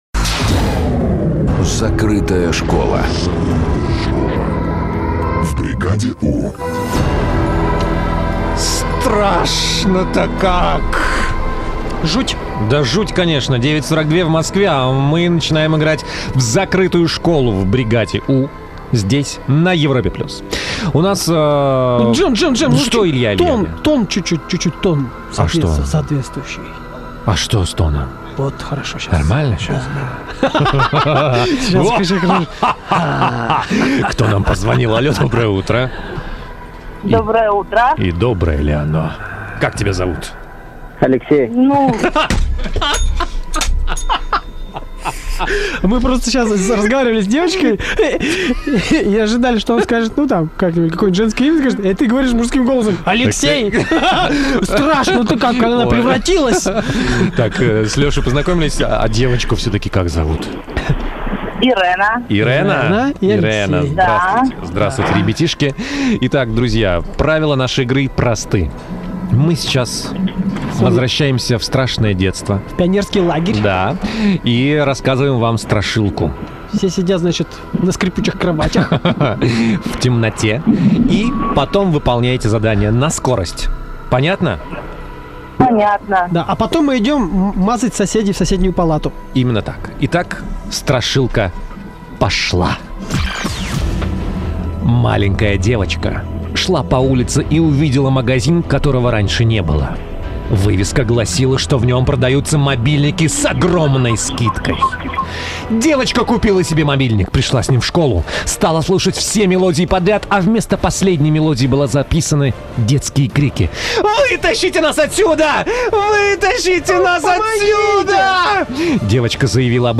Утренняя страшилка на Европе Плюс. Запись эфира.